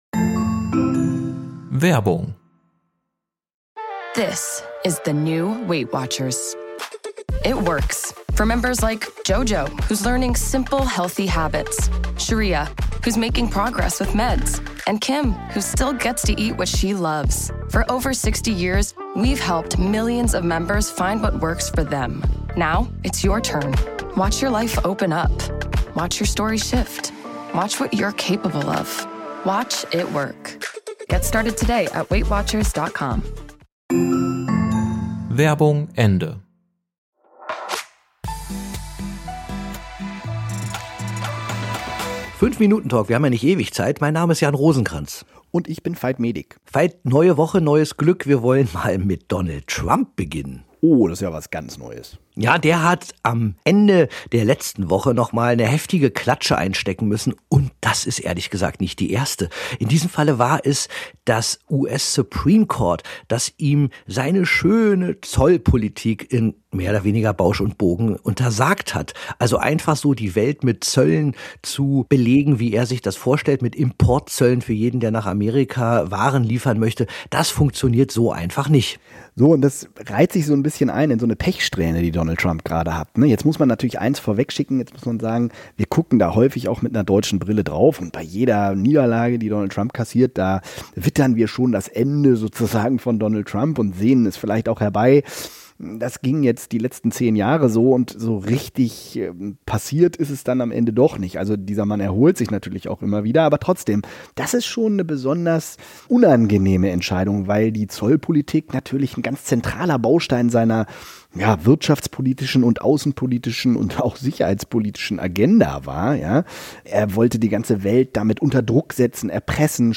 5-Minuten-Talk – wir haben ja nicht ewig Zeit